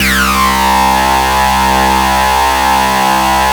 SUPER FUZZ-L.wav